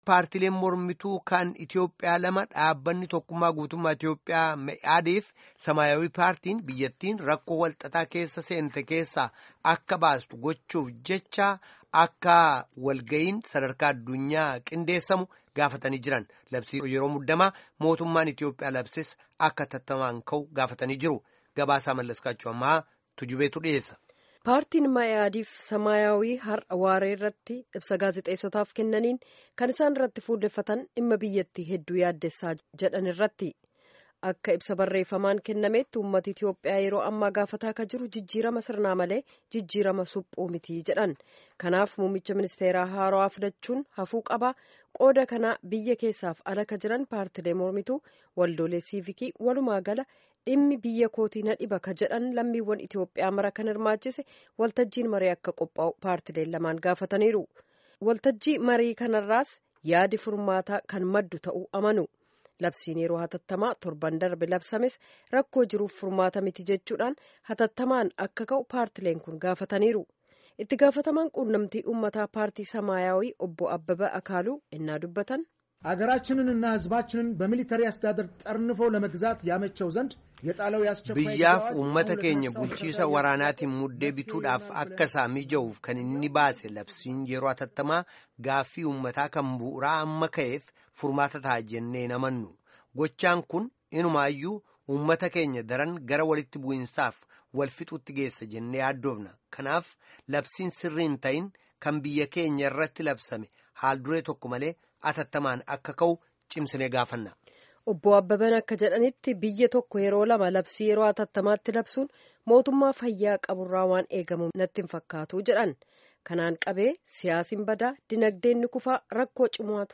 Gabaasa guutuu caqasaa